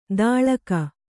♪ dāḷaka